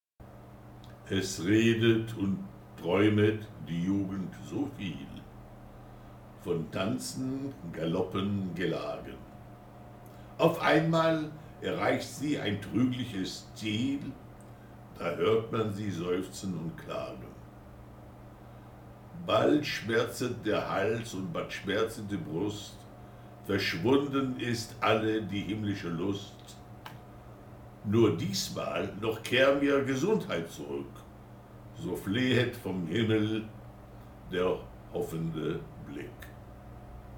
Pronunciation
schubert_der_tanz_pron.mp3